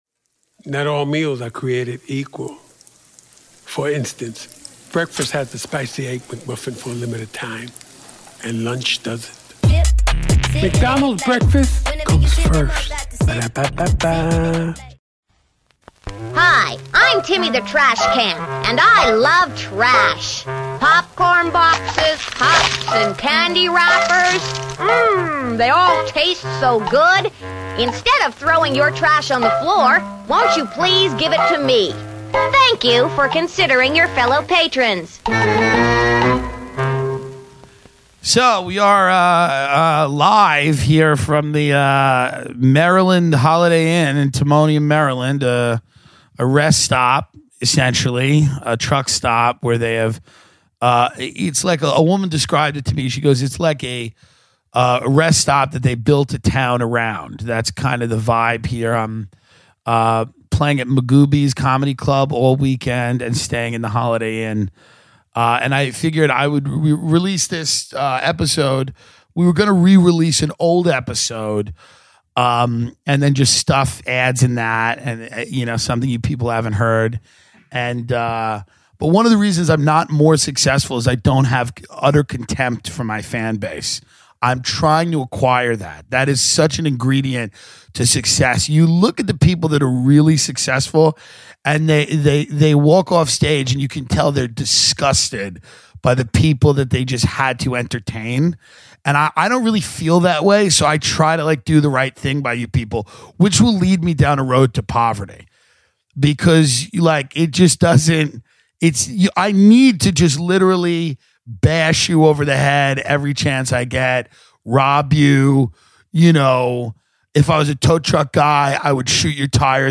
Live from a Holiday Inn in Maryland, Tim talks about people who don't get ahead because they live in fear, why a lot of his friends should die in war, the time he was a juror on a murder trial, and his most recent move to the hills.